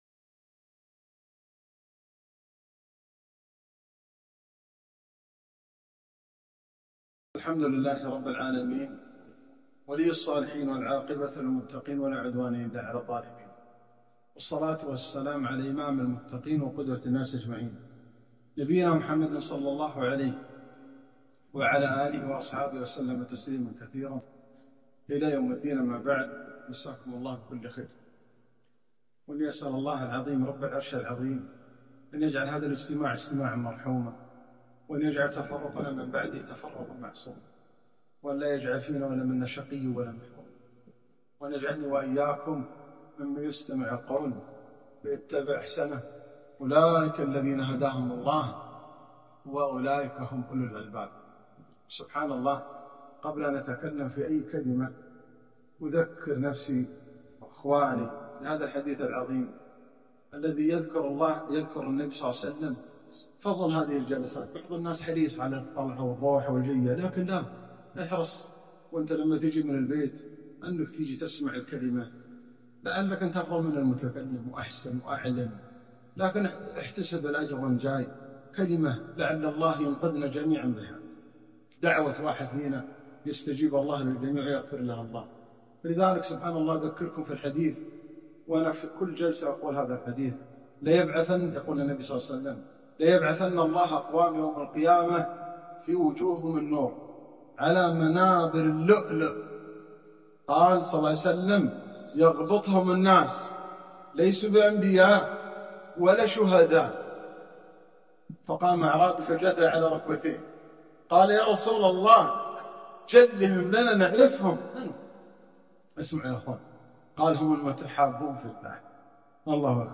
كلمة - ثاني اثنين